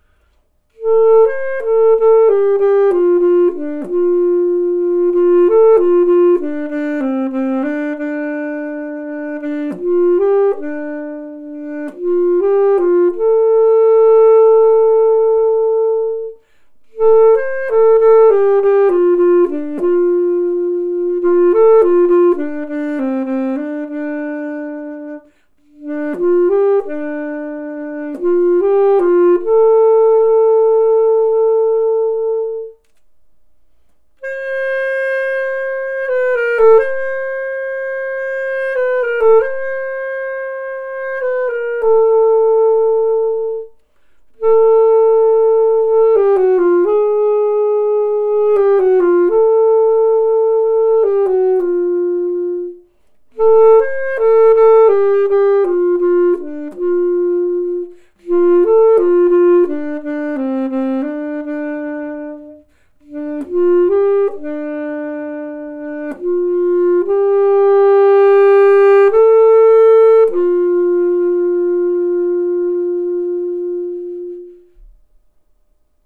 These rough and ready 'test clips' are recorded with two different mics,
SaxB_eq.wav